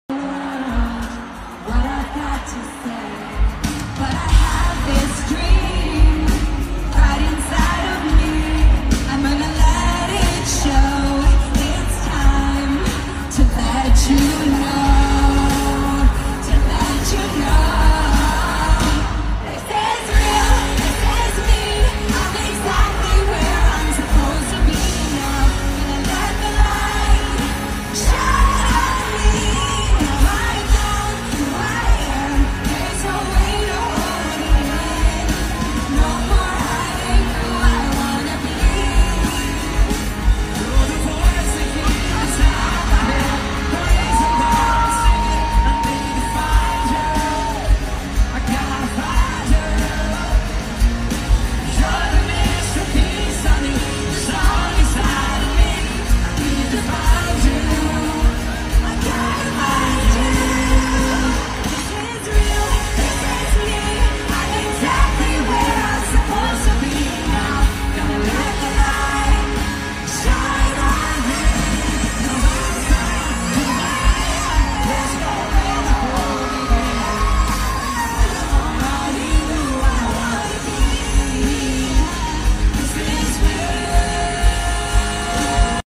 en el MetLife Stadium